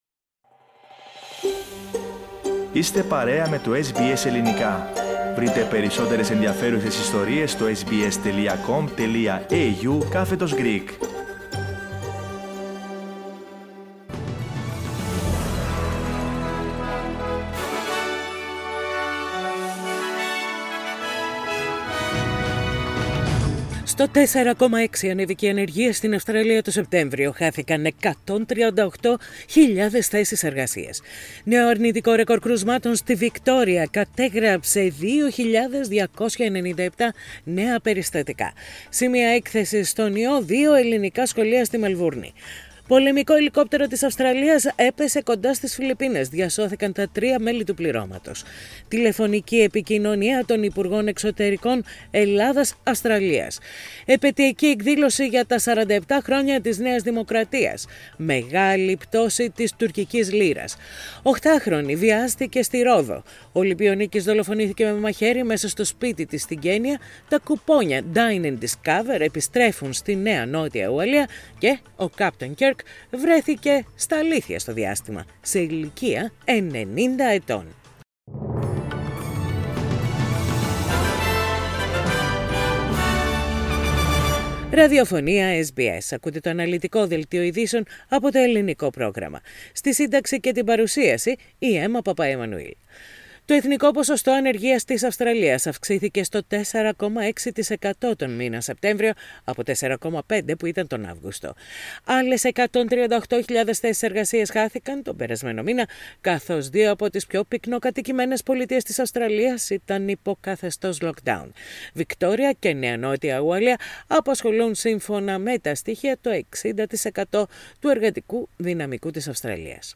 Main news of the day from SBS radio Greek program.